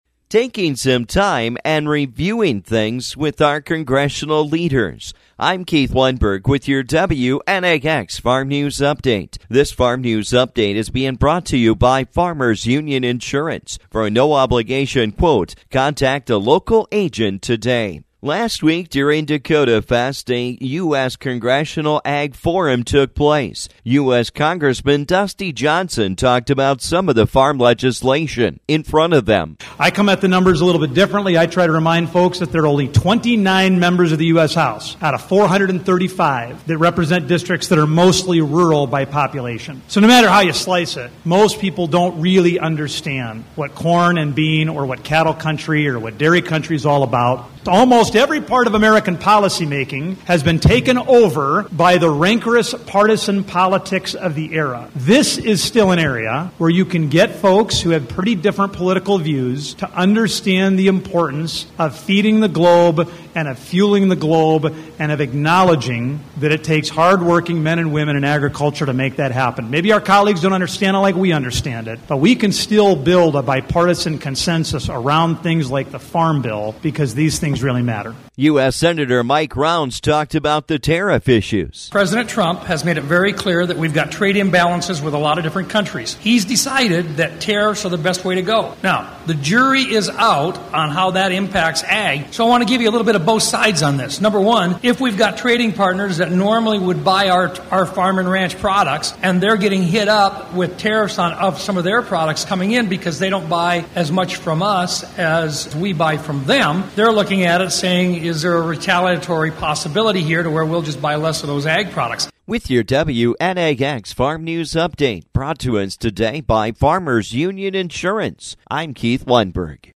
During Dakota Fest last week, we got an update on Farm Legislation and more from our Congressional Leaders.